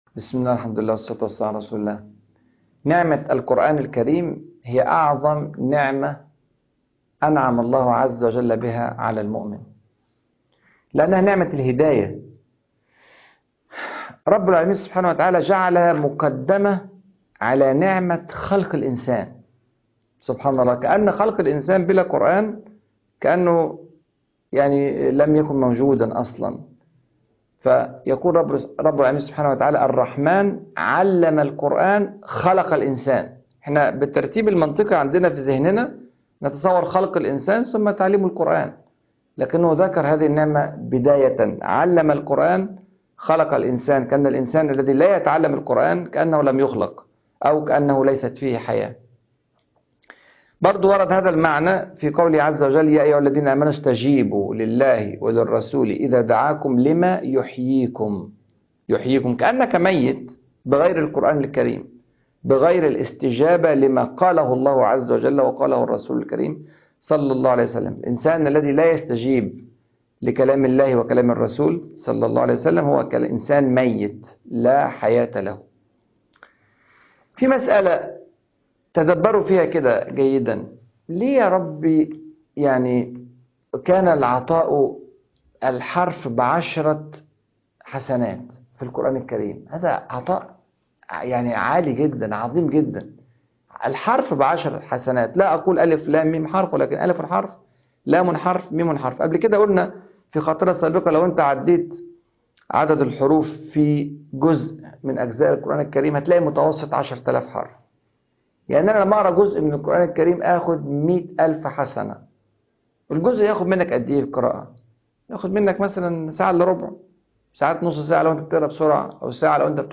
من هم أهل الله في الأرض؟ بعد التراويح - الشيخ راغب السرجانى